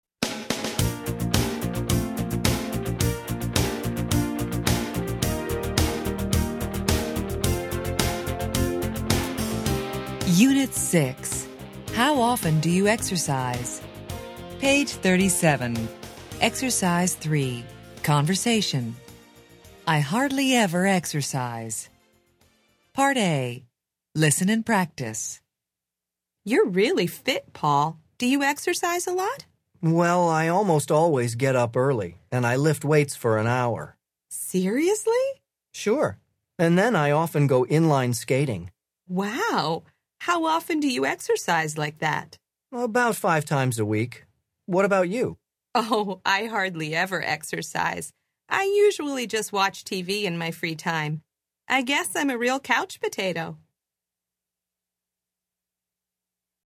Interchange Third Edition Level 1 Unit 6 Ex 3 Conversation Track 17 Students Book Student Arcade Self Study Audio